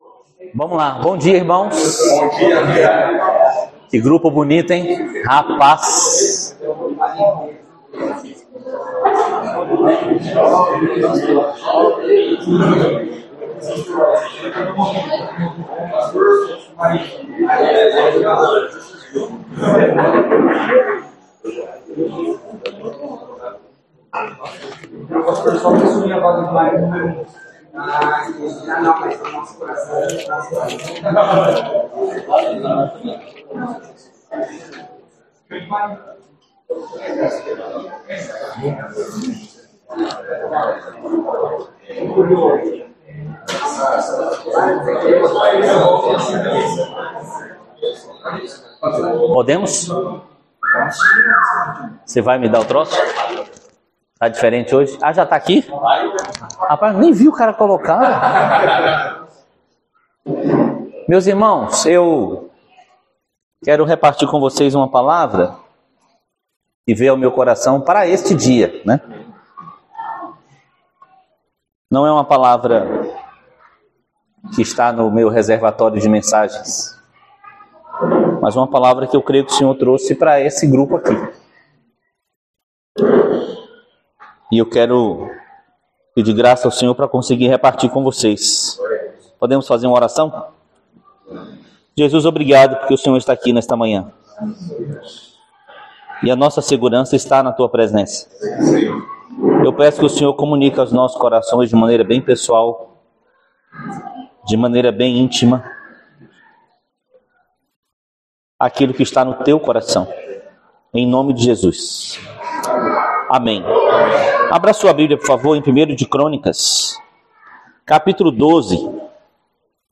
Palavra ministrada aos HOMENS no Encontro de Famílias 2026